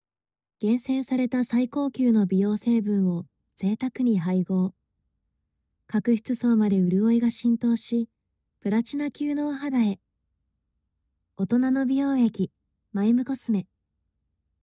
出⾝地・⽅⾔ 山口県・山口弁、関西弁
ボイスサンプル
CM１